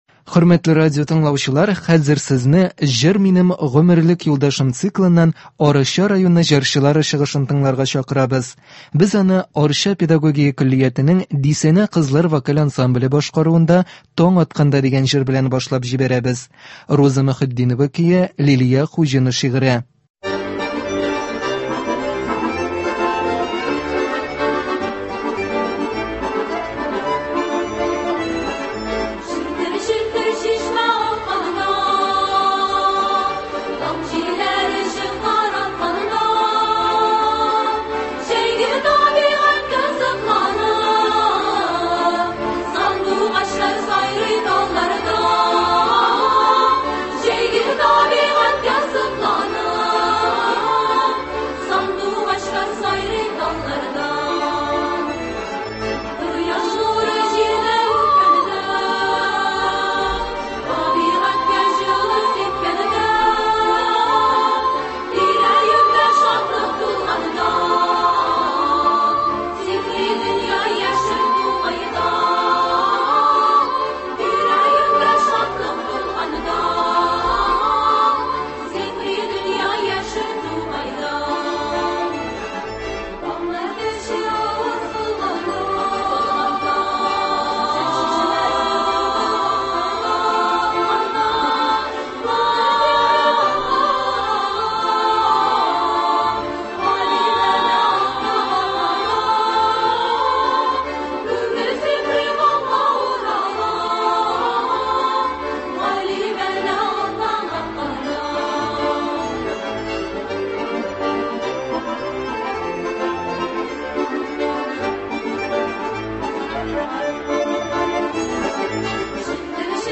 Үзешчән башкаручылар чыгышы.
Концертлар (29.01.24)